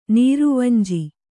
♪ nīruvanji